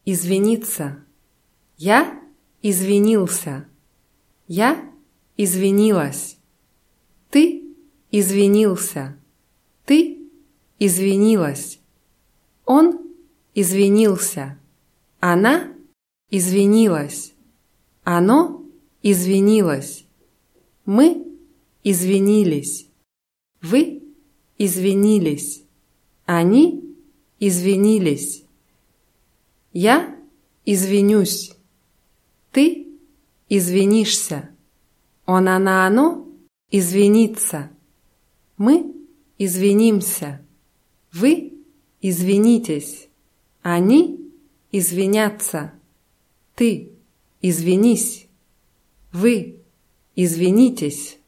извиниться [izwʲinʲítsa]